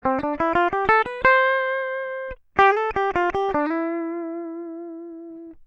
(E) Rear.mp3는 그림 01의 근처를 강하게 해 픽에 각도를 붙여 연주한 소리입니다. 엣지가 효과가 느껴지는 굳은 소리로 들릴 것입니다.
이 2개의 소리는 같은 기타로 세팅도 완전히 같은 상태로 녹음하고 있습니다.